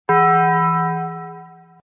Taco Bell Bong Sound Button: Unblocked Meme Soundboard
Play the iconic Taco Bell Bong sound button for your meme soundboard!